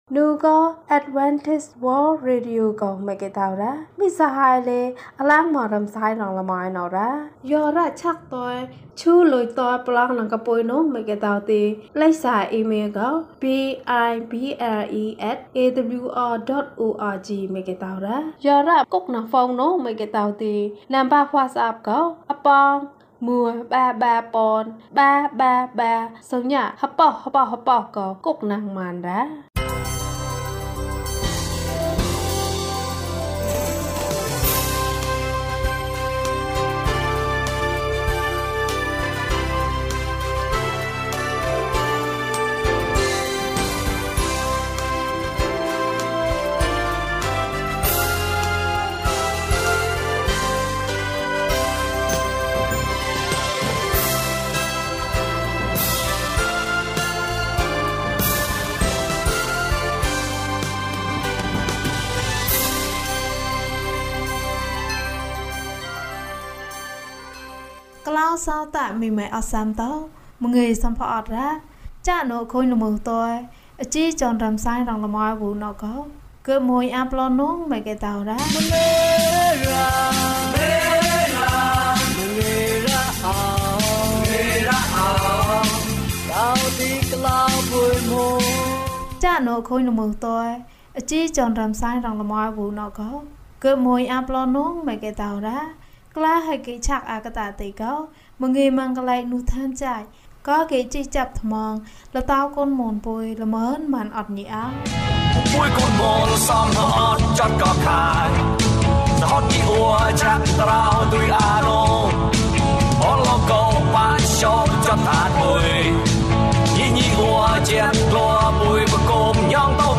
သူသည် အမှန်တရားဖြစ်သည်။၀၂ ကျန်းမာခြင်းအကြောင်းအရာ။ ဓမ္မသီချင်း။ တရားဒေသနာ။